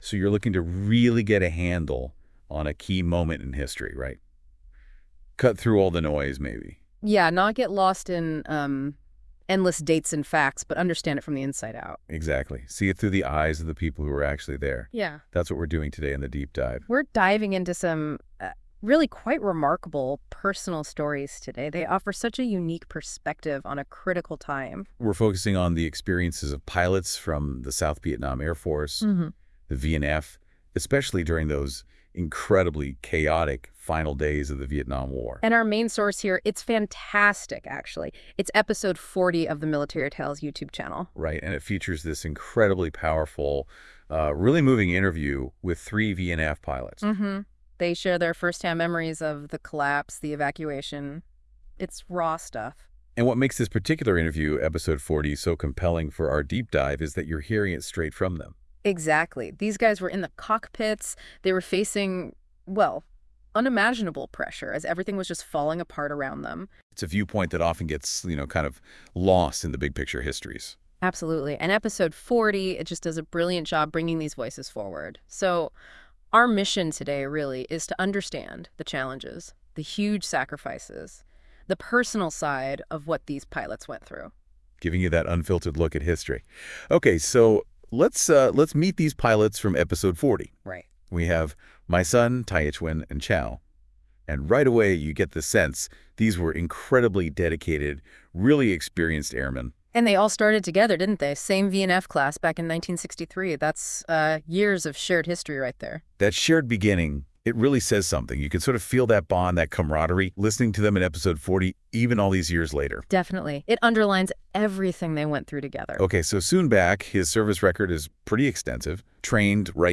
Episode 40 of Military Tales features a poignant conversation with three veterans of the South Vietnamese Air Force (VNAF)